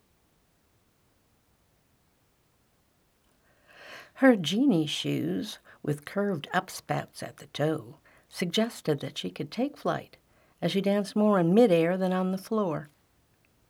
At volume setting #9: